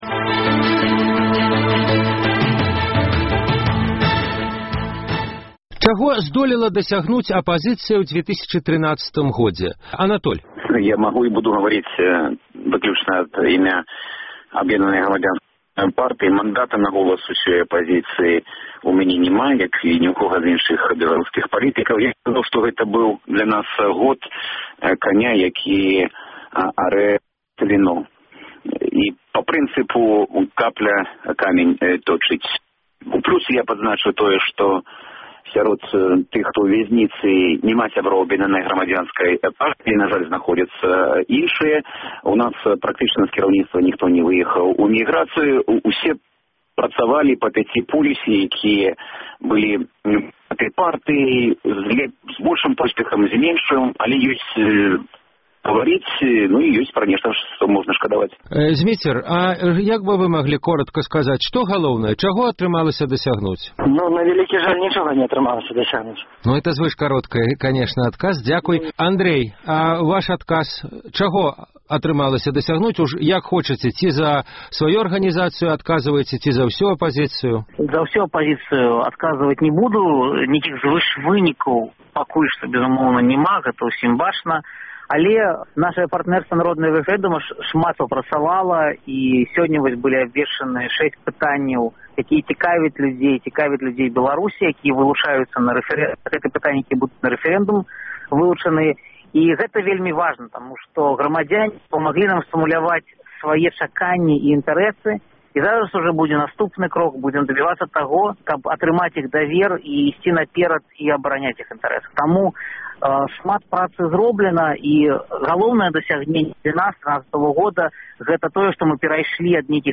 Ці праіснуе падзел на блёкі Талака і Народны рэфэрэндум да 2015 году? Ці стала апазыцыя бліжэй да грамадзтва? Якія ўрокі ўкраінскага Эўрамайдану для Беларусі? Гэтыя тэмы абмяркоўваюць у перадачы «Праскі Акцэнт»